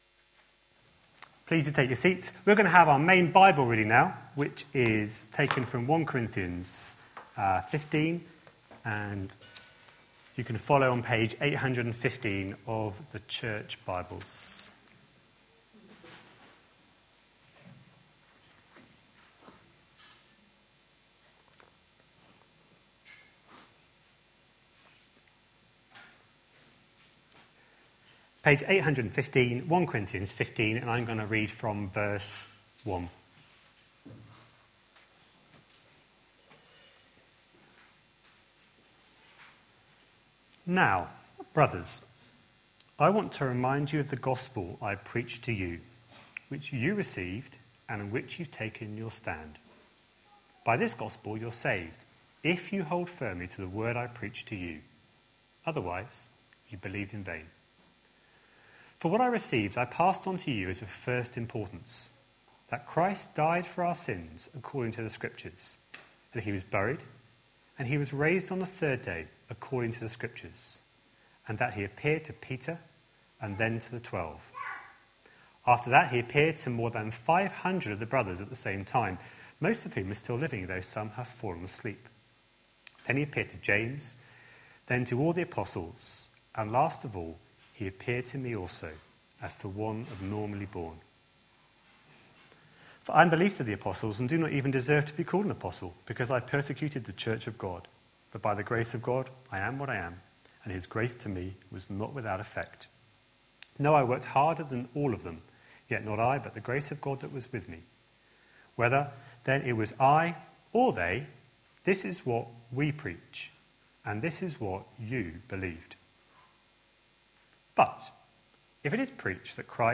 A sermon preached on 21st February, 2016, as part of our The God who makes himself known series.